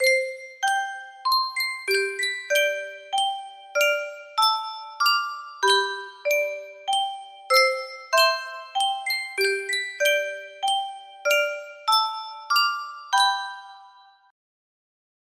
Yunsheng Custom Tune Music Box - Unknown Tune 7 music box melody
Full range 60